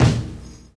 Kick (7).wav